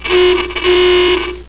racecar.mp3